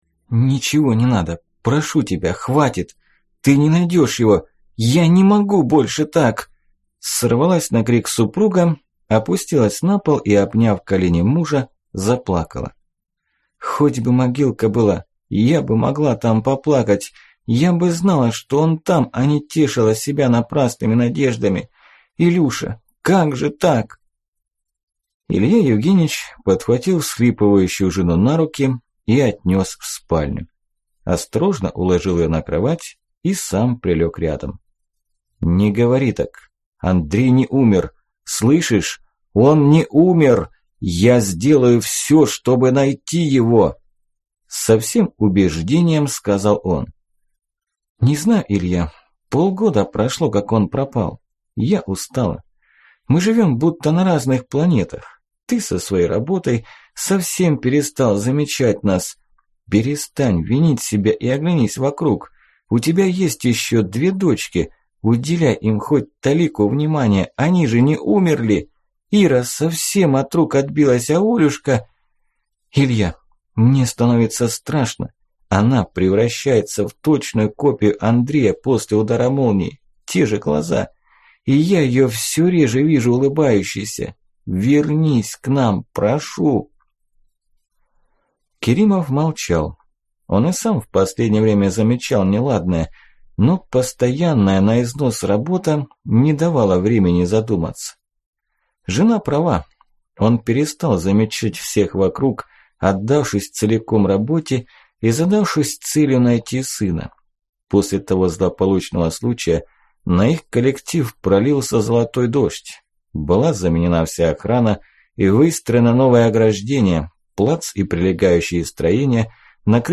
Аудиокнига Жестокая сказка | Библиотека аудиокниг
Прослушать и бесплатно скачать фрагмент аудиокниги